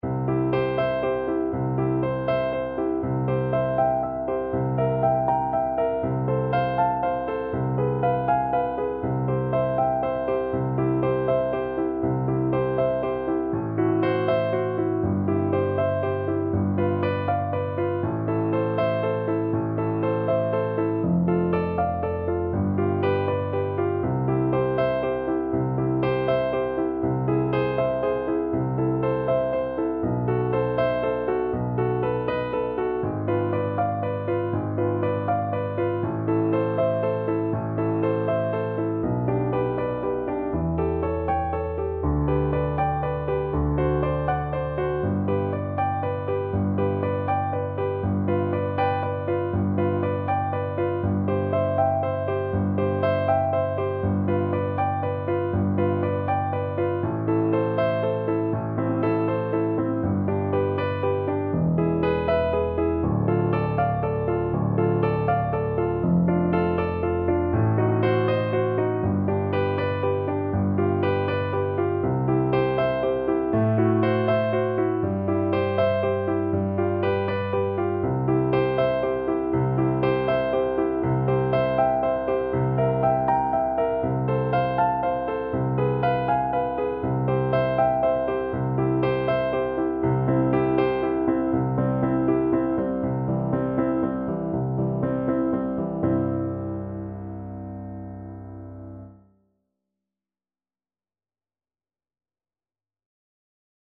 Andante
Classical (View more Classical Tenor Voice Music)